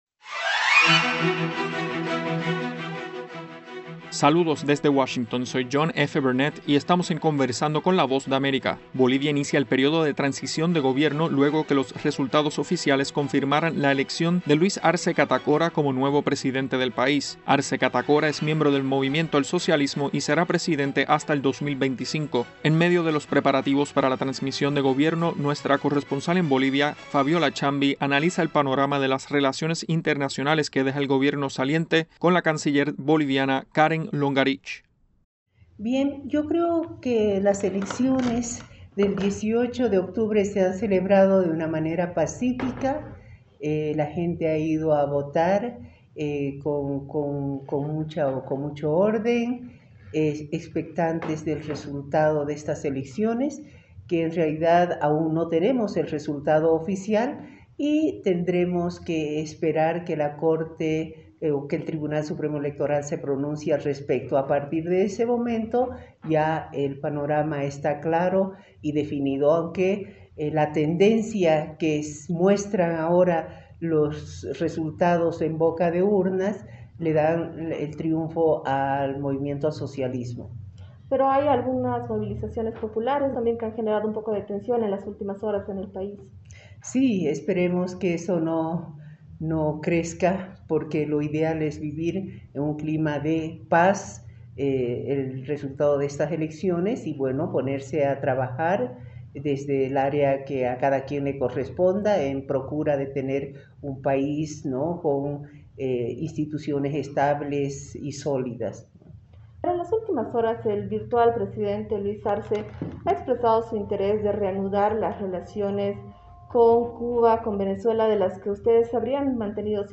Conversamos con Karen Longaric, canciller de Bolivia, abordando las relaciones internacionales del gobierno saliente y las perspectivas que enfrentará la nueva Administración.